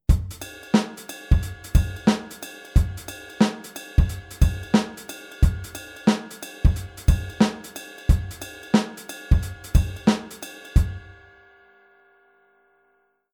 Paradiddle Kapitel 1 → Shuffle-Offbeat? Eigentlich nur seitenverdreht?
Damit das Gehirn wirklich hört, dass es sich hier um einen anderen Shuffle handelt, sollte man unbedingt in der rechten Hand den Sound wechseln. Meine Empfehlung: Das Kopfbecken (head/bell).
Wenn das nicht fetzig klingt???